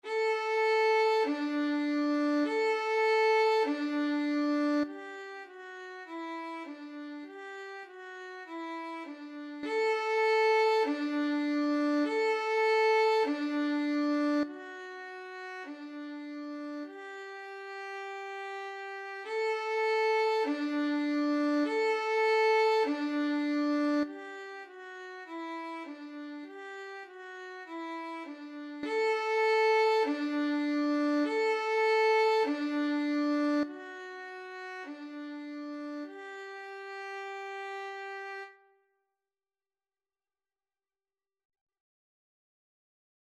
4/4 (View more 4/4 Music)
Violin  (View more Beginners Violin Music)
Classical (View more Classical Violin Music)